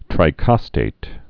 (trī-kŏstāt)